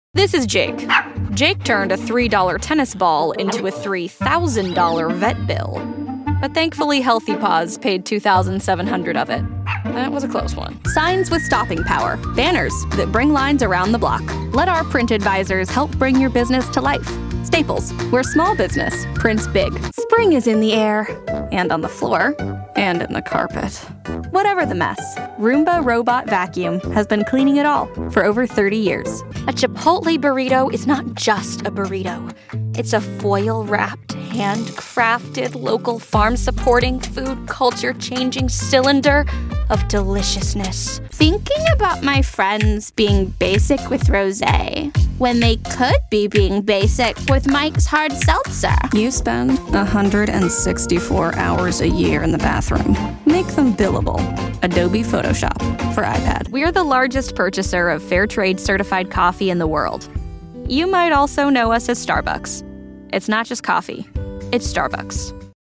Engels (Amerikaans)
Jong, Natuurlijk, Stoer, Vriendelijk, Speels
Commercieel